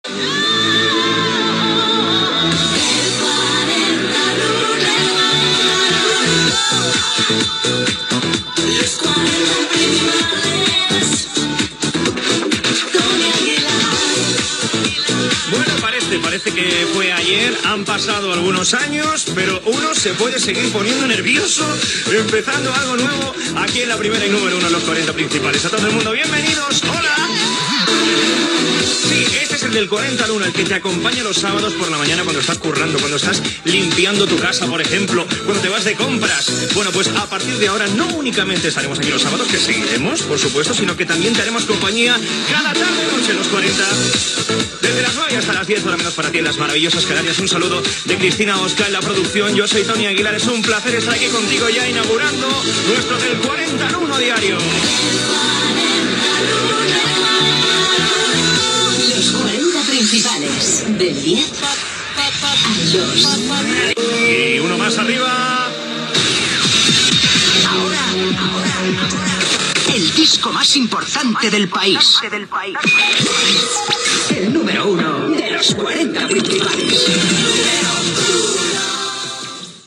Careta del programa, presentació inicial